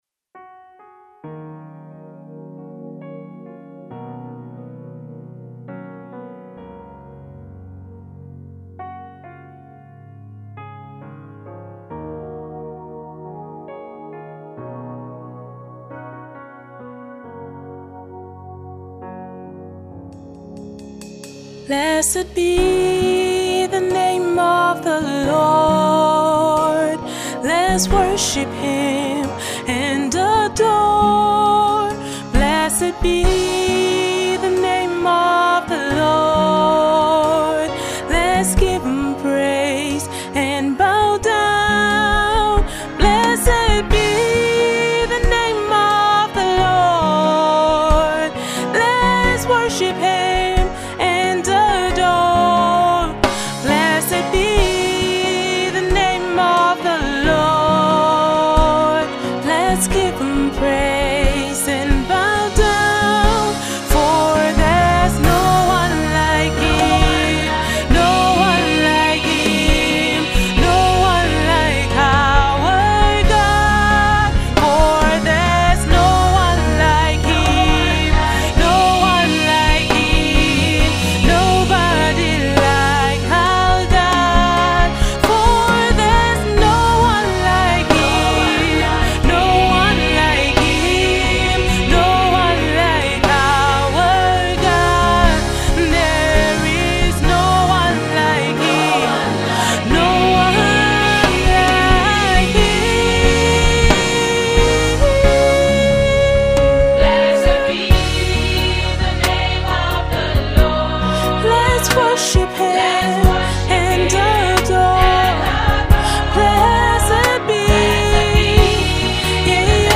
Jos based sensational Gospel singer and song writer